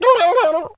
Animal_Impersonations
turkey_ostrich_hurt_gobble_11.wav